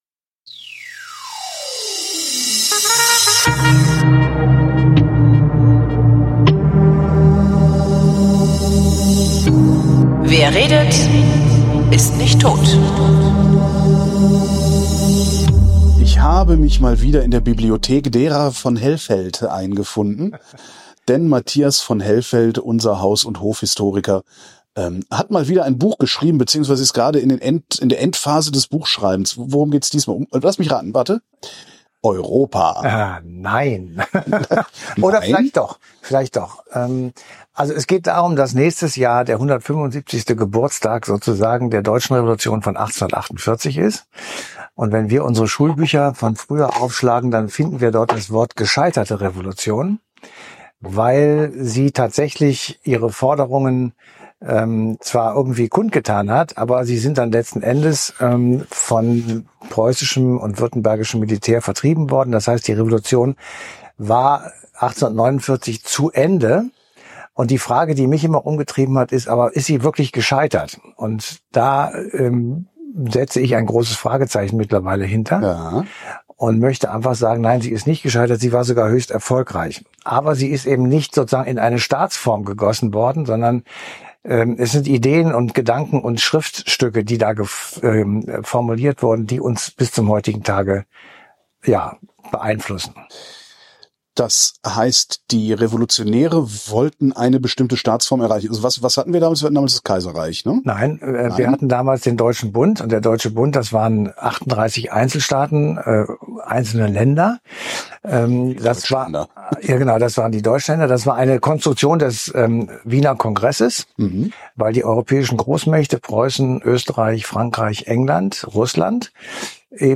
Bitte entschuldigt die gelegentlichen Tonstörungen